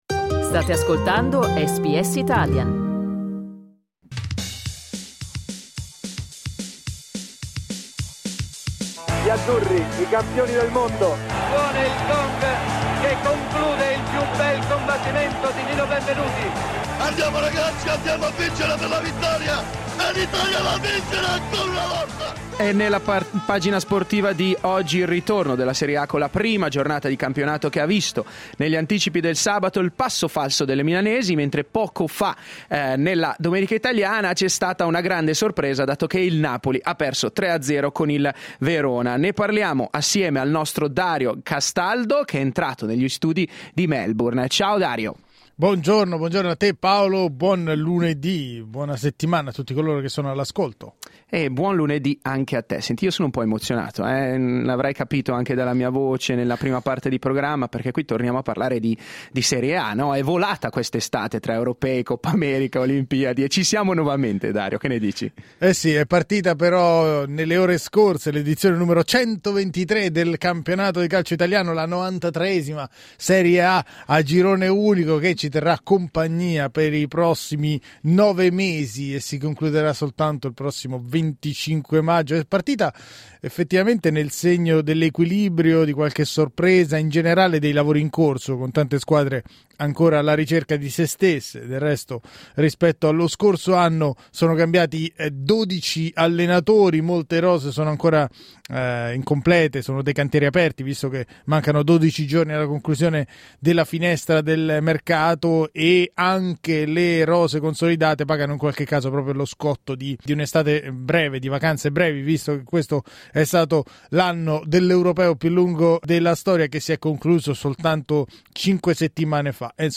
Due semisconosciuti al debutto in Serie A travolgono il Napoli e lanciano il Verona in testa alla classifica. Ascolta le parole dei protagonisti della prima giornata del campionato italiano.